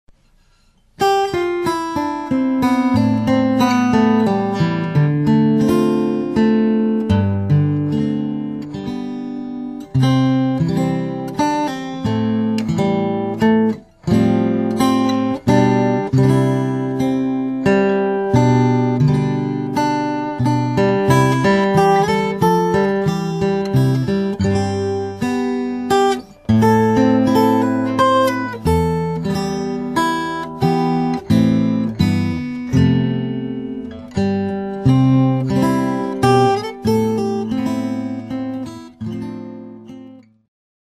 Solo arrangement